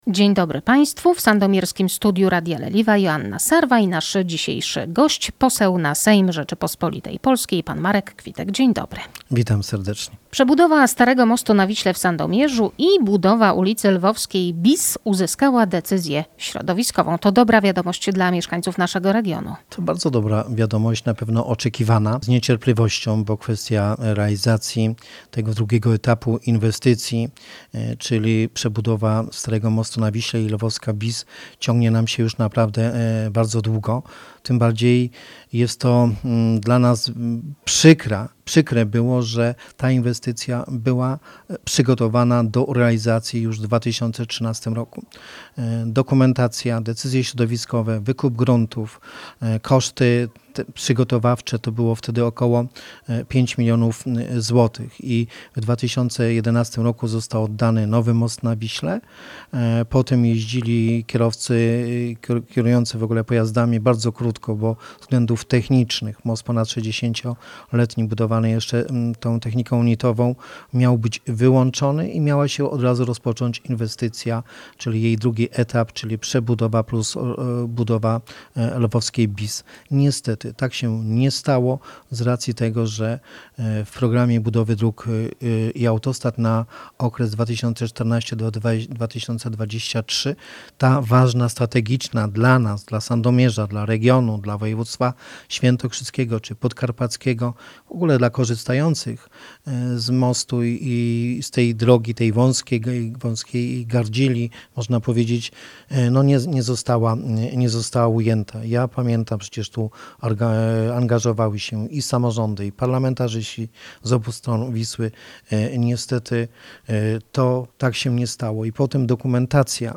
Rozmowa z posłem na sejm RP Markiem Kwitkiem: